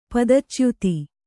♪ padachyuti